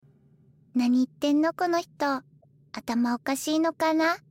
もう麻衣さん声可愛すぎる